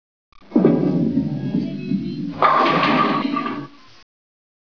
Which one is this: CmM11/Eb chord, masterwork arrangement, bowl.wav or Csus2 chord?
bowl.wav